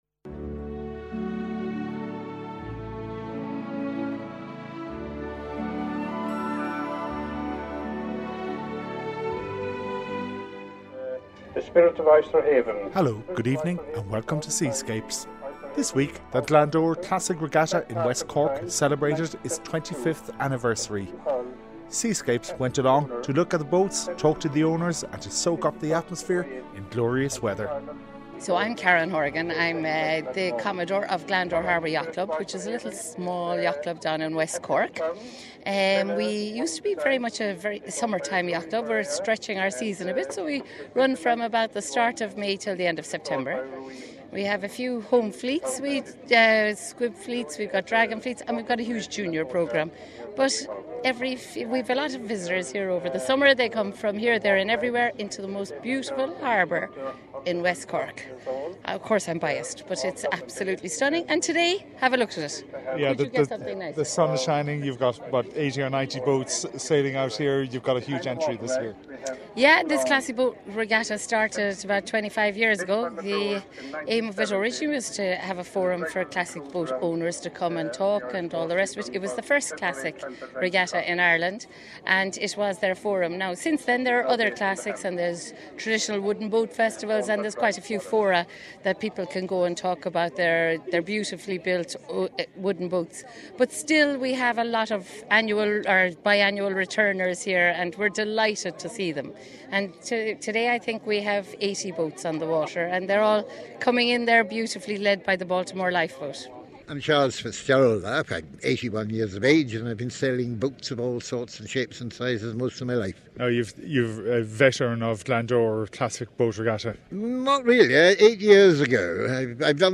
Jeremy Irons was interviewed at the Glandore Classic Regatta on 28 July 2017 for the Seascapes RTE Podcast.